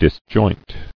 [dis·joint]